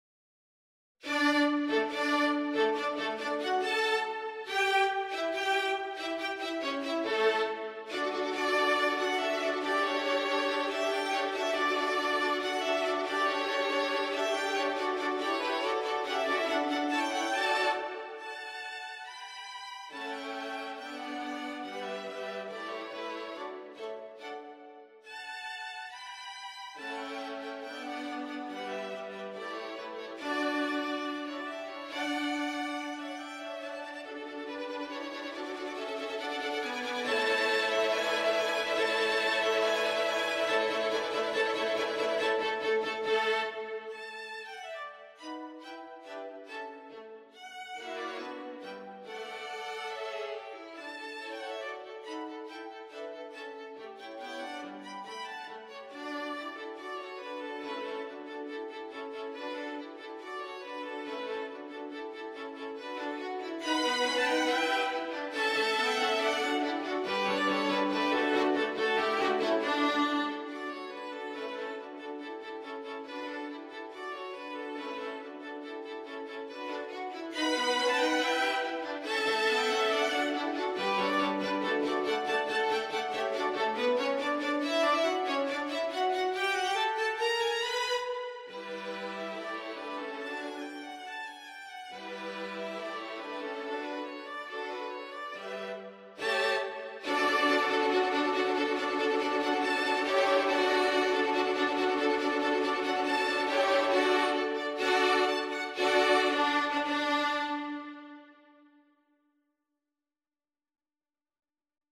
An abridged arrangement for violin quartet